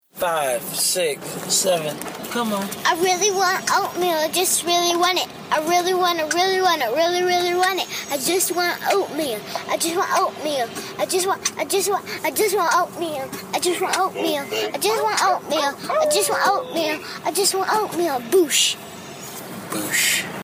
Voice Memo